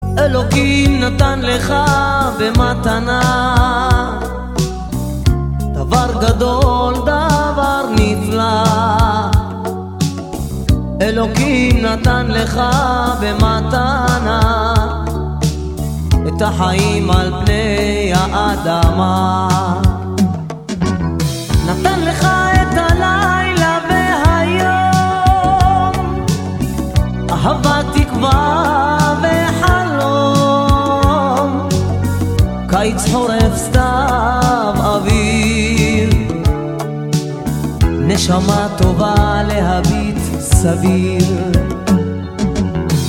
романтические